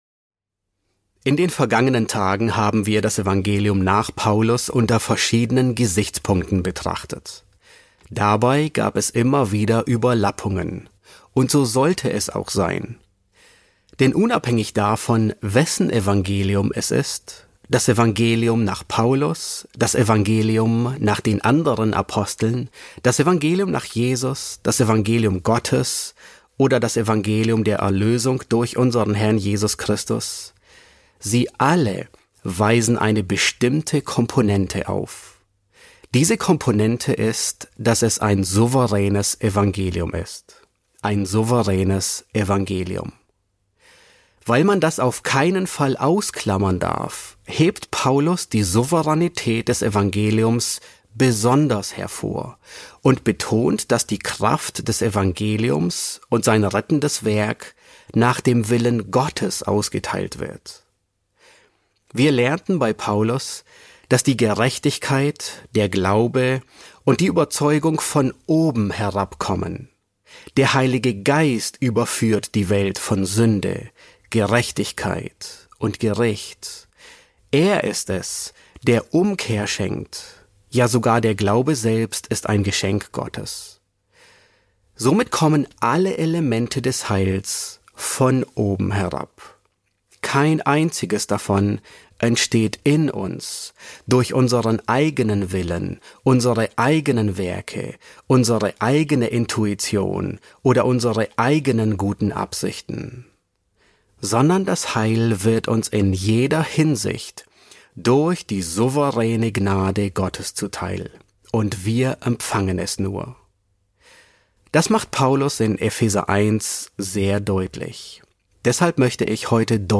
Predigten auf Deutsch